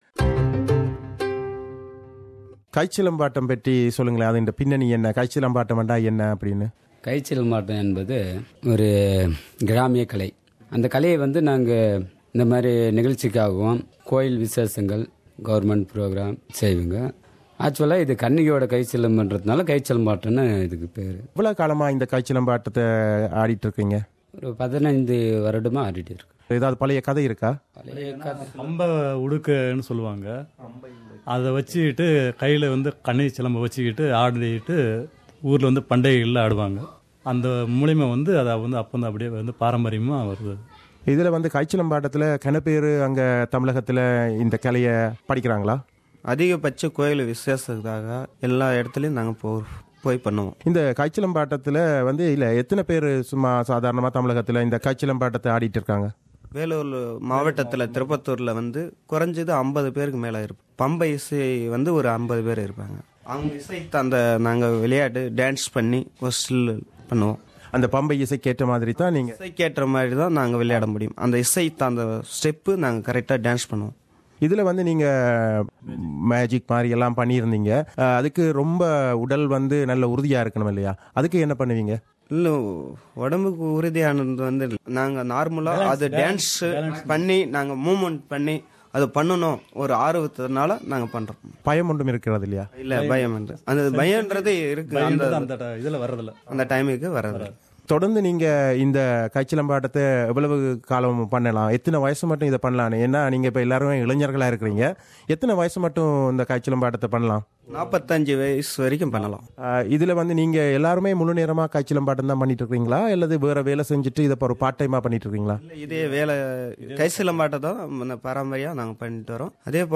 Interview with Tamil Folk artists